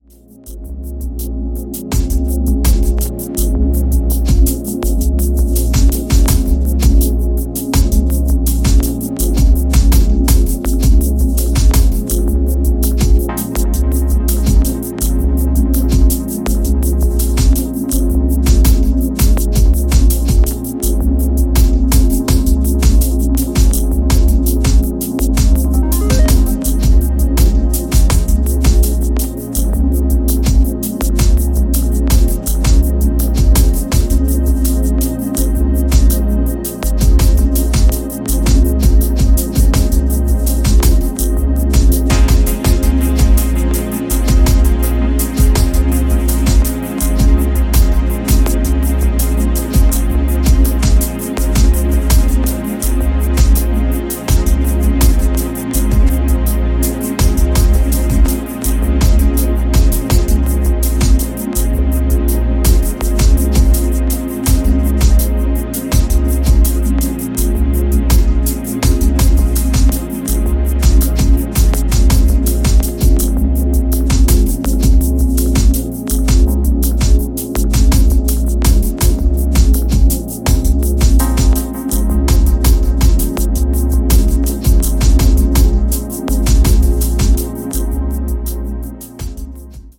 Electronix Ambient Breaks Dub Techno